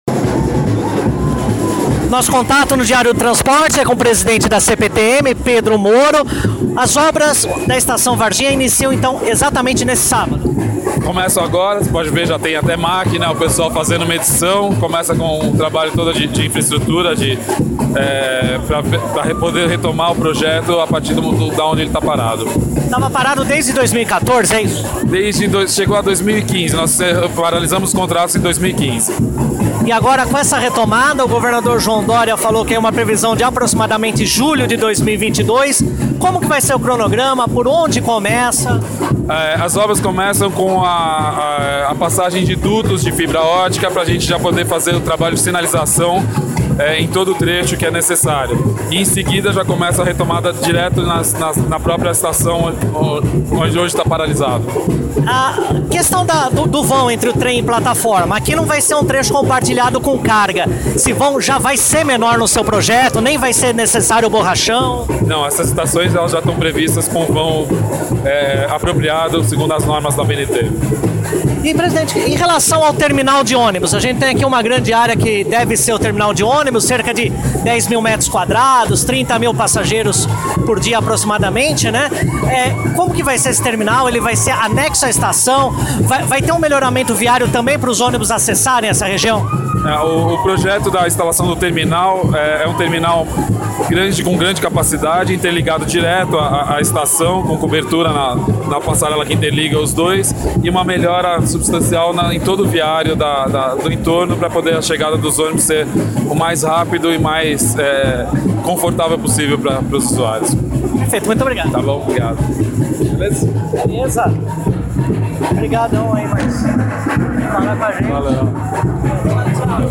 Ouça o áudio da entrevista com o presidente da CPTM, Pedro Moro: